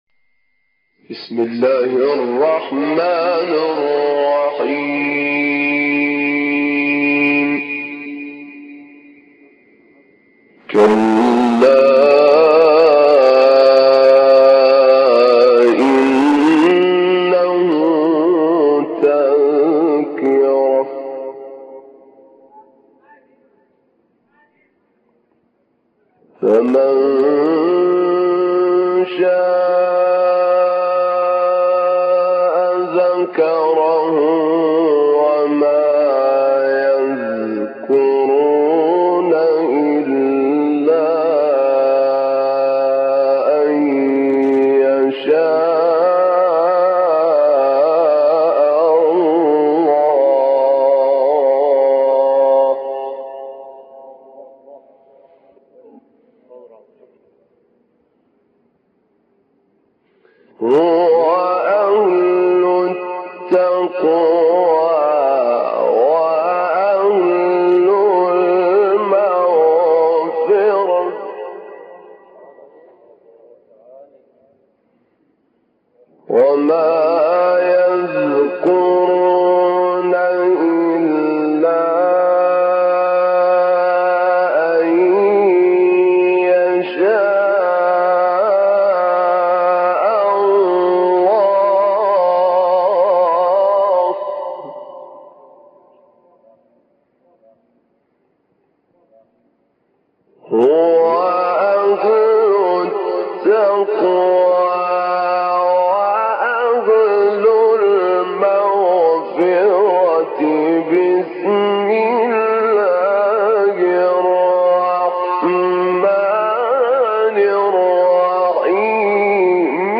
تلاوت مجلسی مدثر ، قیامه استاد طاروطی | نغمات قرآن | دانلود تلاوت قرآن